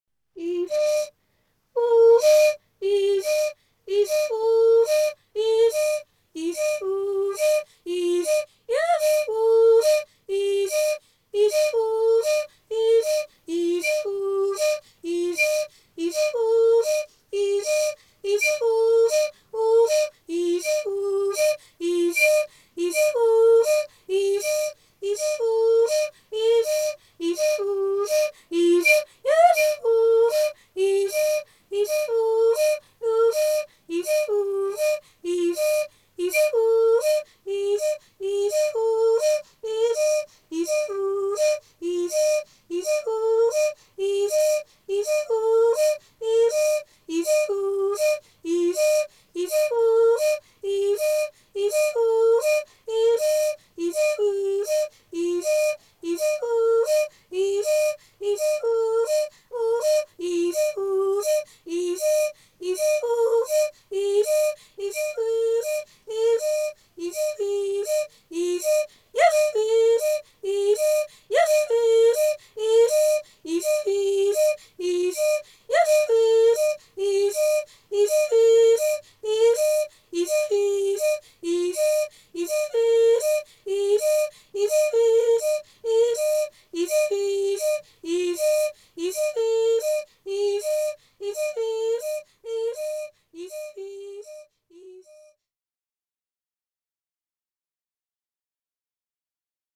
Hoquet voco-instrumental (musique pygmée)
hoquet-voco_instrumental.mp3